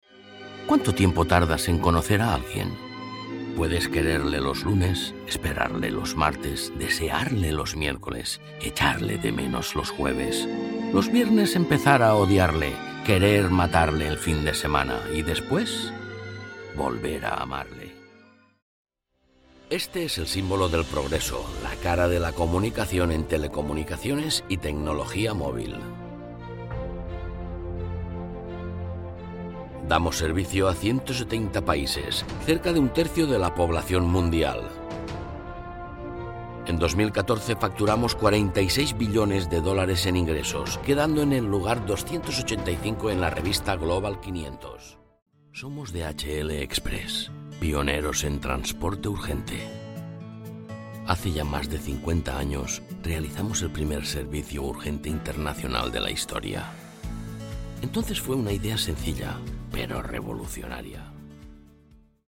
HOMBRES (de 35 a 50 años)